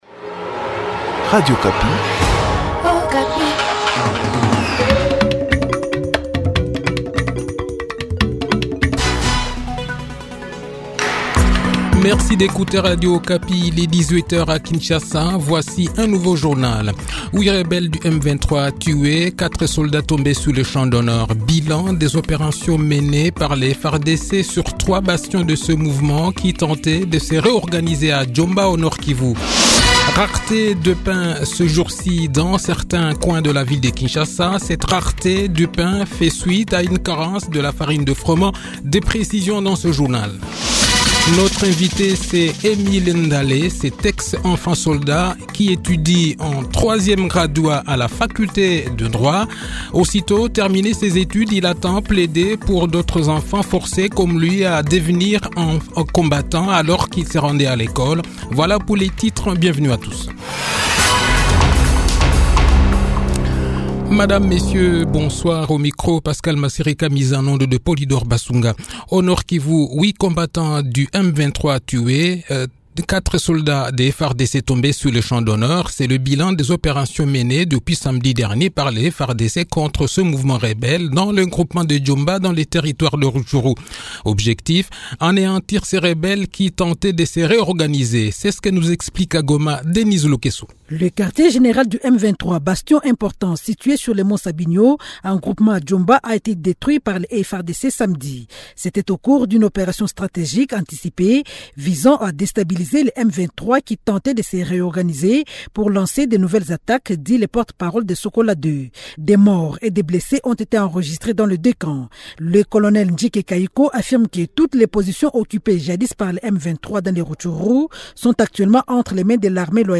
Le journal de 18 h, 22 mars 2022